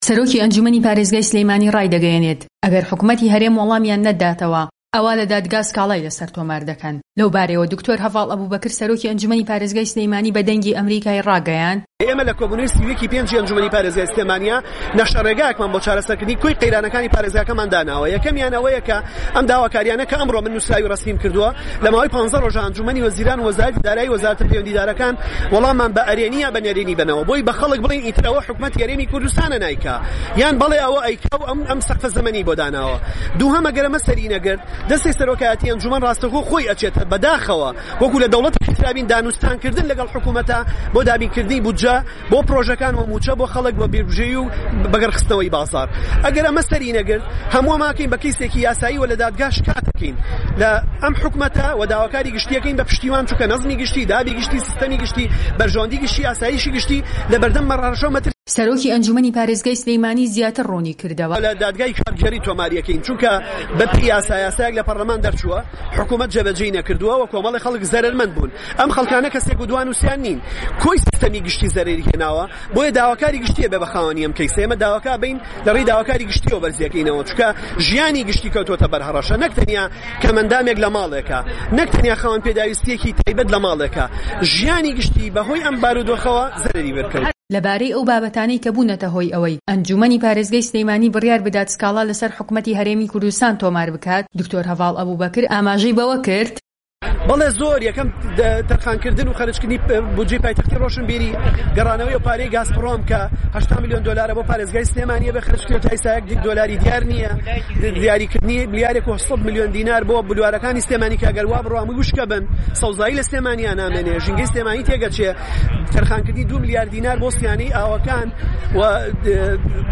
وتووێژ لەگەڵ هەڤاڵ ئەبوبەکر